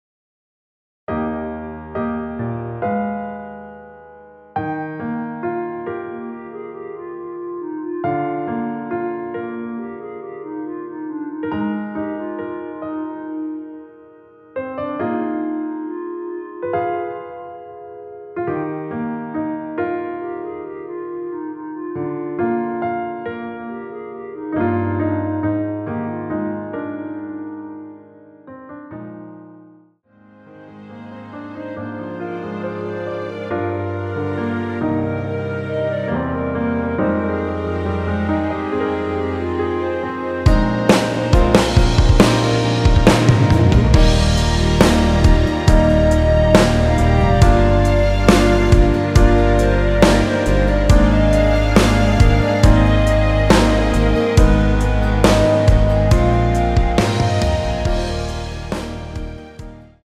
노래 들어가기 쉽게 전주 1마디 만들어 놓았습니다.(미리듣기 확인)
원키에서(-1)내린 (1절앞+후렴)으로 진행되는 멜로디 포함된 MR입니다.
Eb
앞부분30초, 뒷부분30초씩 편집해서 올려 드리고 있습니다.